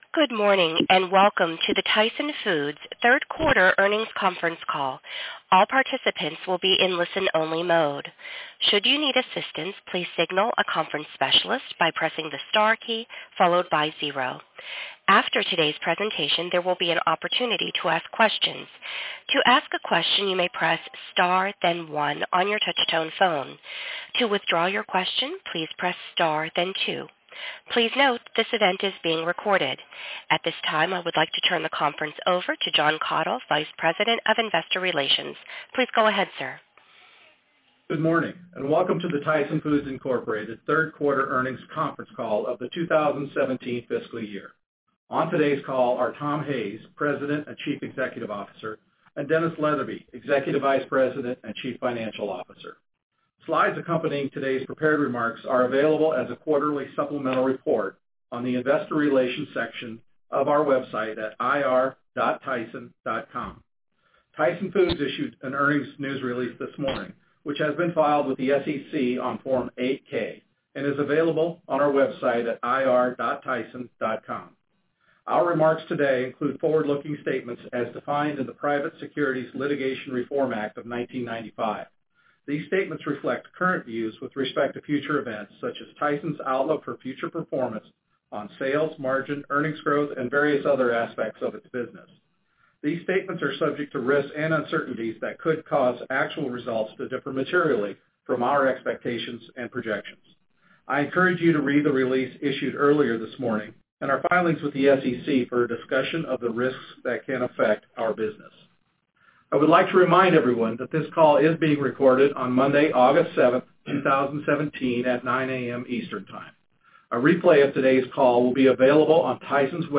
Tyson Foods Inc. - Q3 2017 Tyson Foods Earnings Conference Call